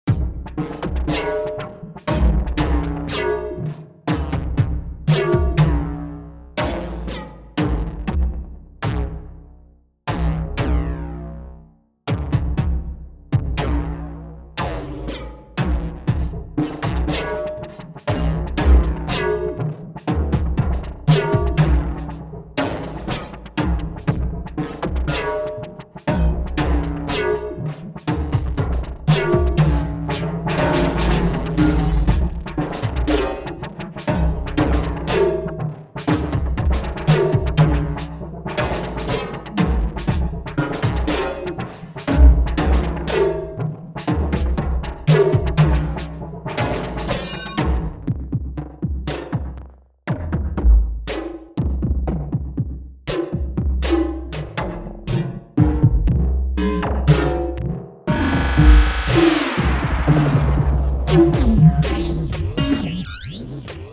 Digitone